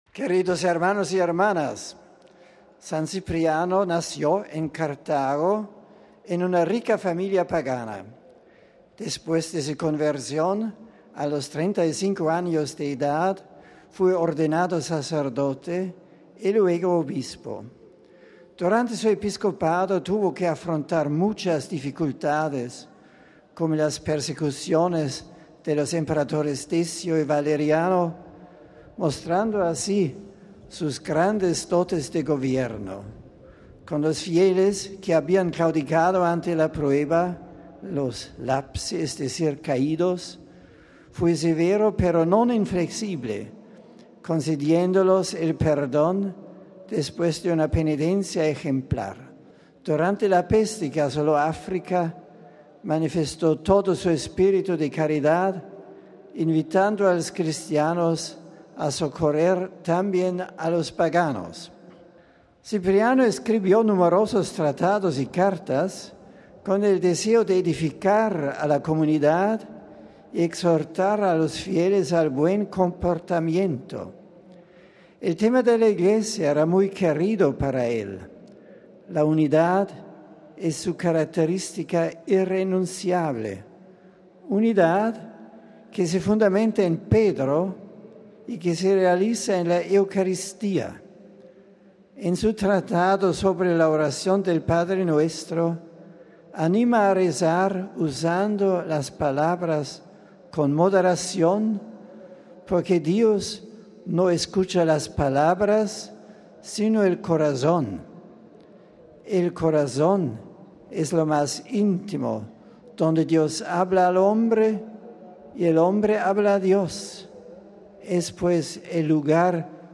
Como es tradicional, el Papa ha resumido en otras lenguas su catequesis de ésta, que ha sido la audiencia número 20 de este año y en la que han participado más de treinta mil peregrinos provenientes de numerosos países.
Este ha sido el resumen que de su catequesis ha hecho el Santo Padre en español para los peregrinos de nuestra lengua presentes en la Plaza de San Pedro: RealAudio